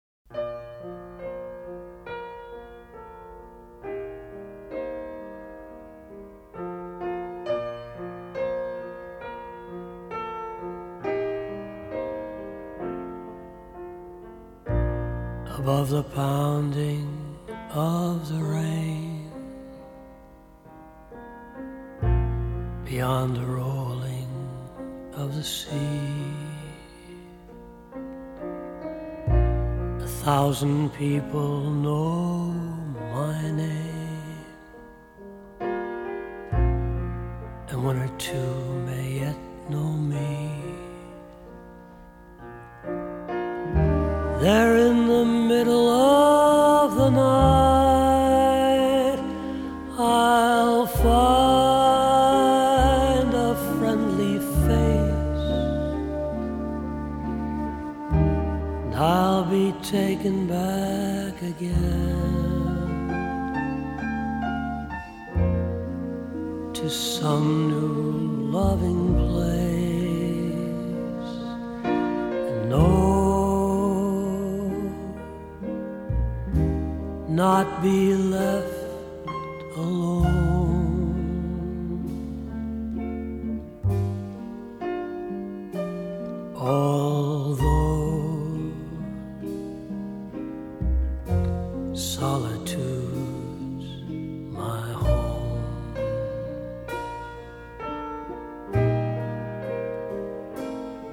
★柔和而富磁性的歌聲，輕緩悠揚的爵士風情，為您在夜間點上一盞綻放溫暖光芒的燈。
低沉富磁性的嗓音更增添了歲月的風采，傳統爵士的鋼琴、吉他、薩克斯風外，更以大提琴、笛子等增添浪漫悠揚的感性